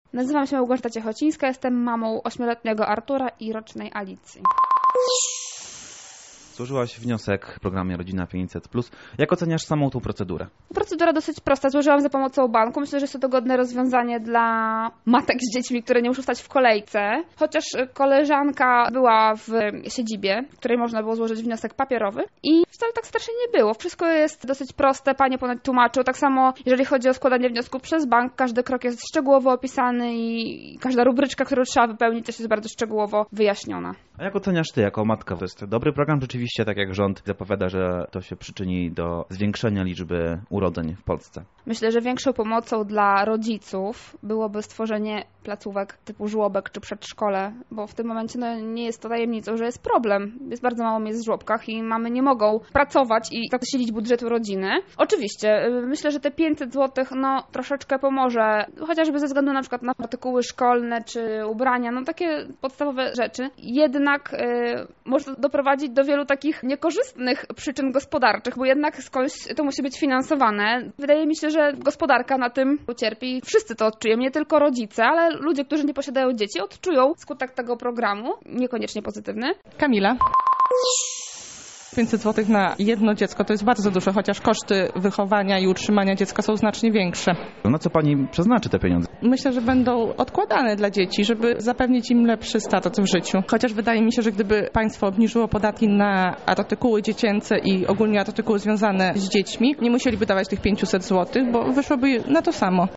tydzien-500-plus-matkaa.mp3